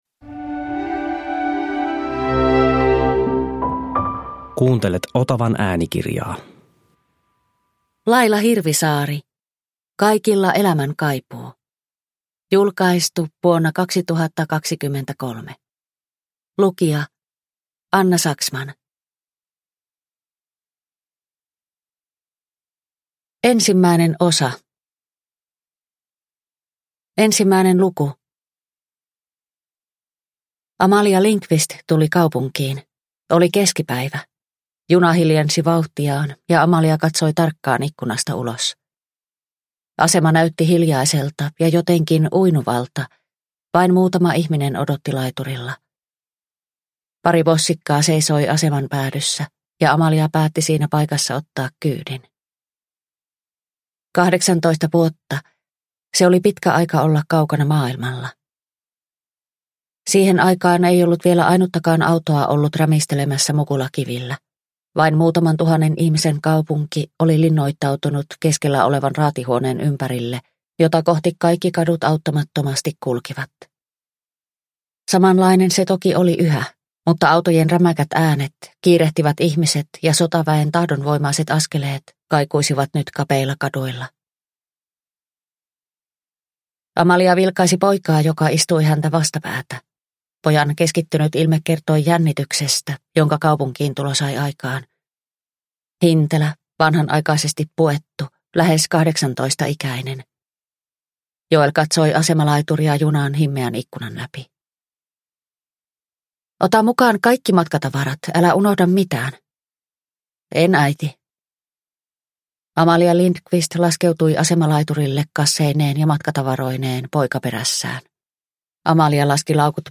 Kaikilla elämän kaipuu – Ljudbok – Laddas ner